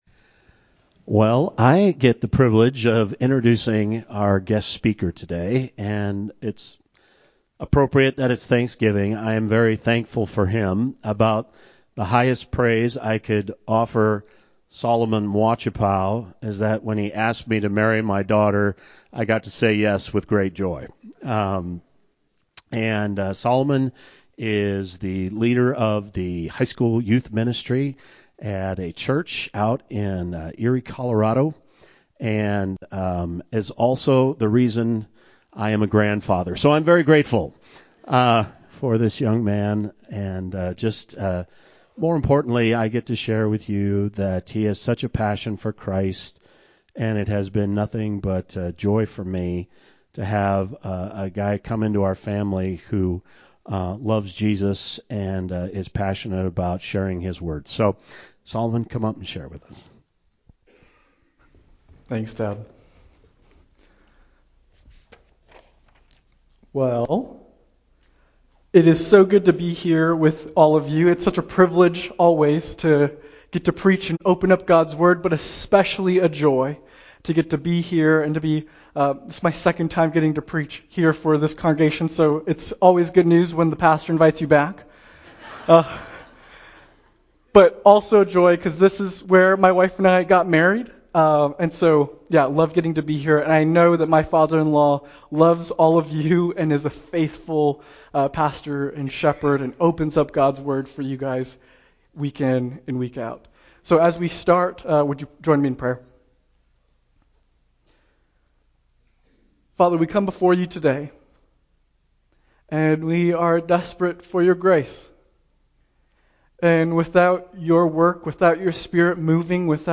Passage: I Thessalonians 5:16-18 Service Type: Sunday morning Download Files Notes Bulletin « That’s All